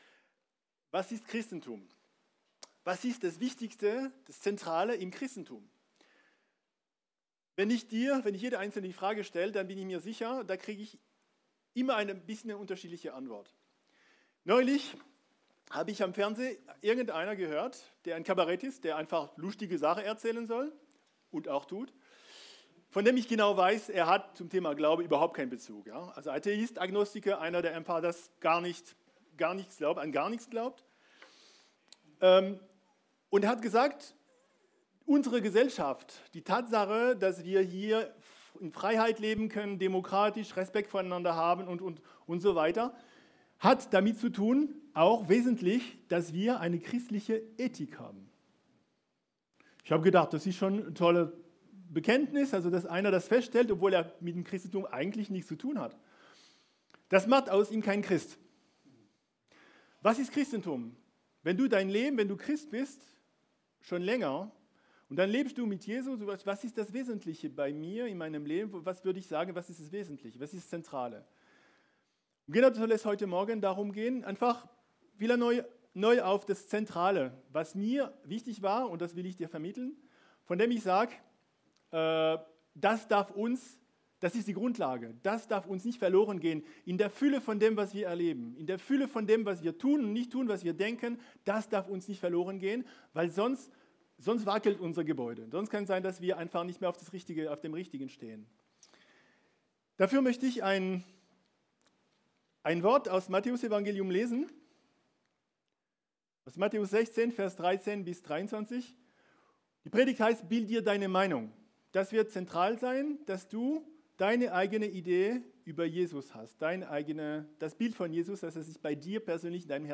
Mit dieser Predigt wollen wir dich einladen zu forschen, zu suchen und zu entdecken.